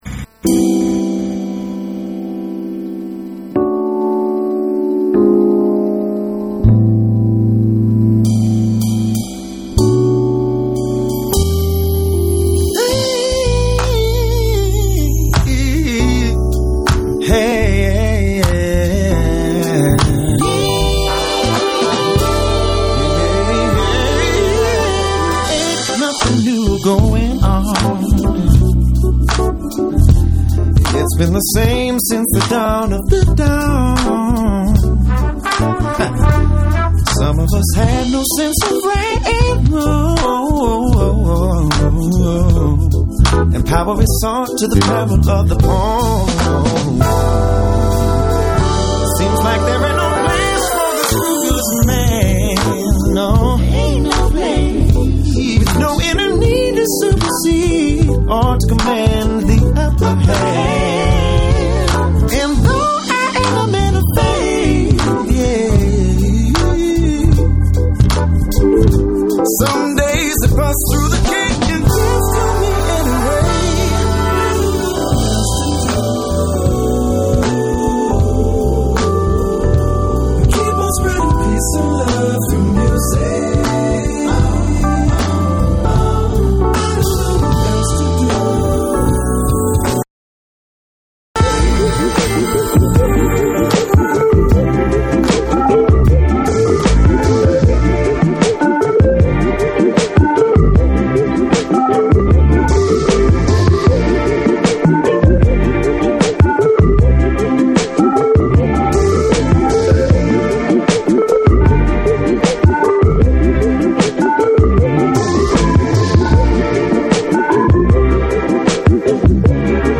JAPANESE / BREAKBEATS / MIX CD